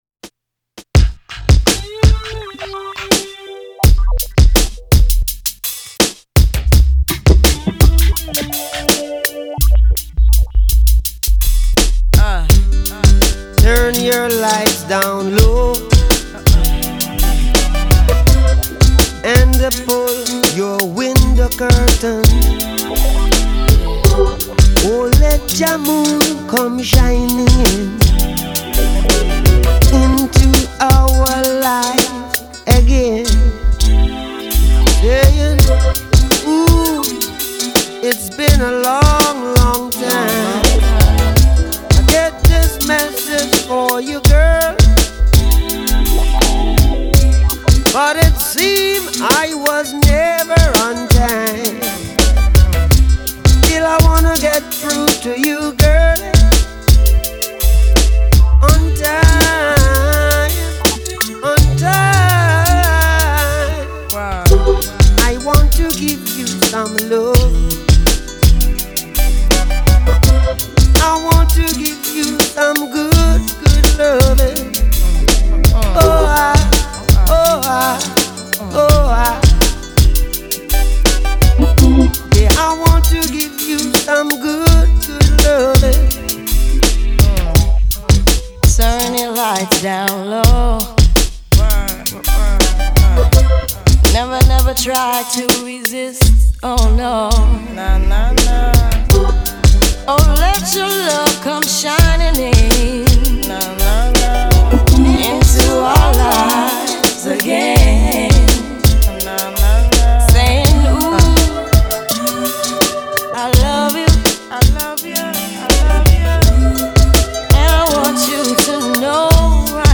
• Жанр: Reggae